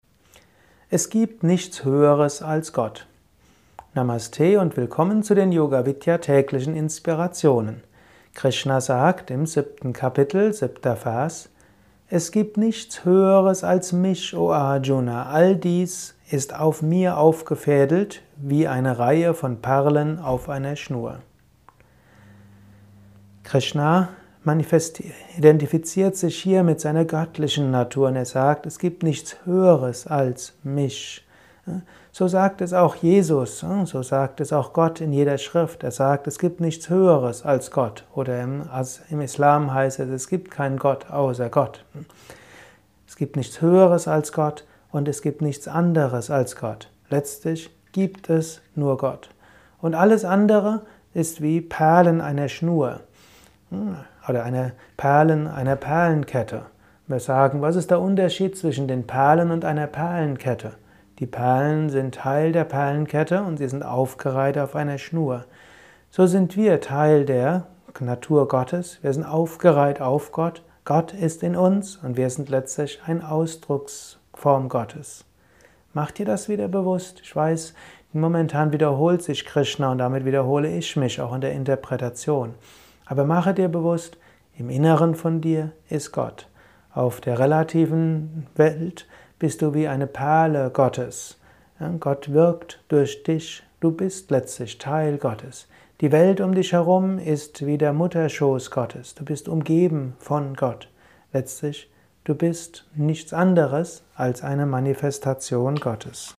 Dies ist ein kurzer Kommentar als Inspiration